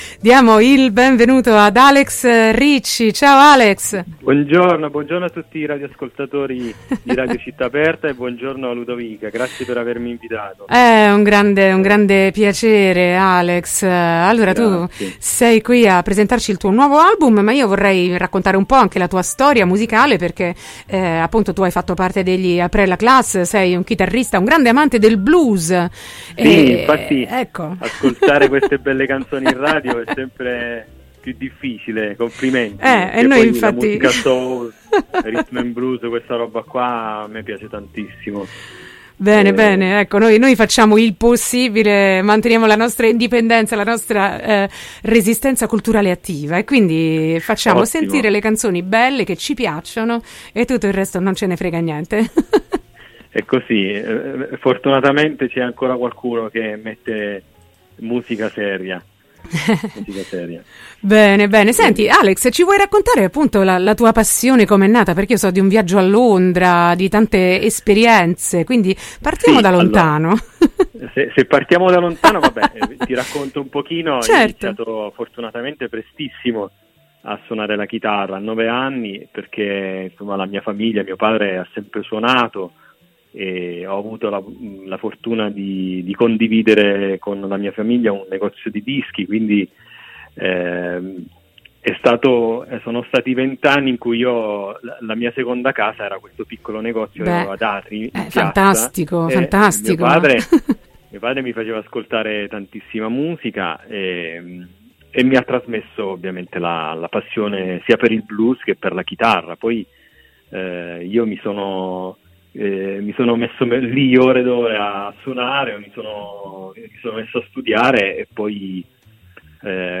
La Verità: intervista